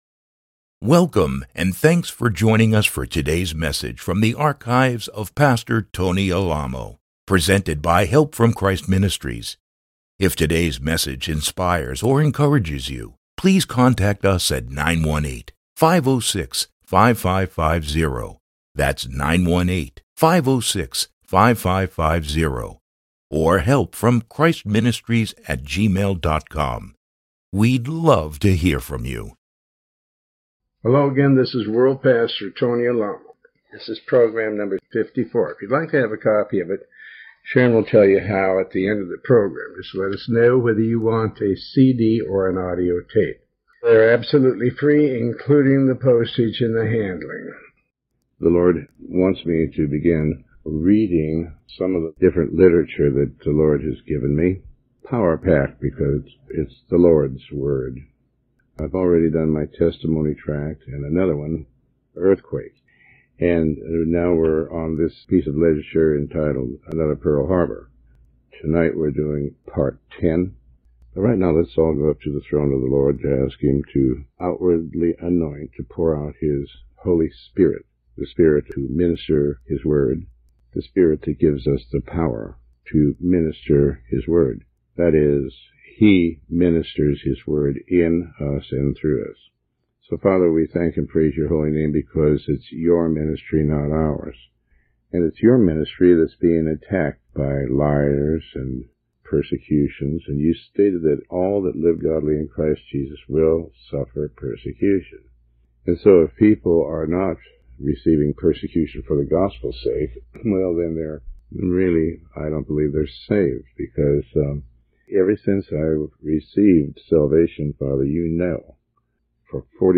Sermon 54